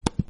btn-active-sound.mp3